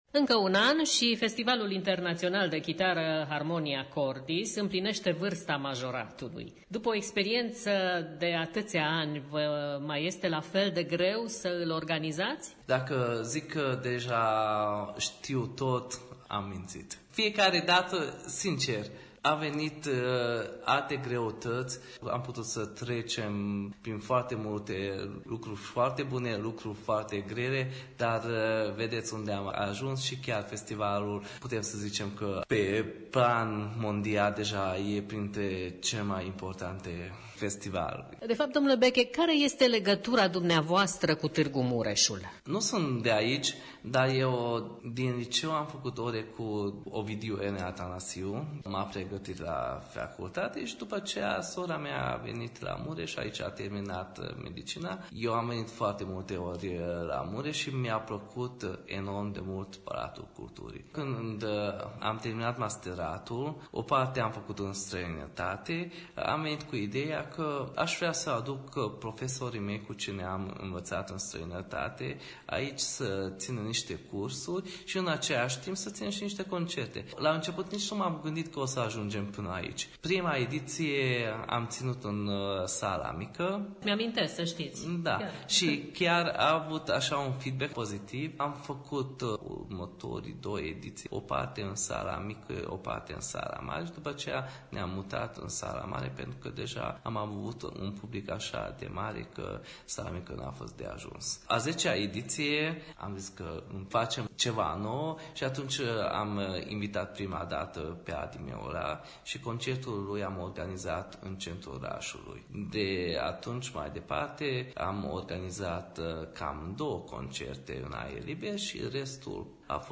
mic interviu